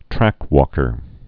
(trăkkər)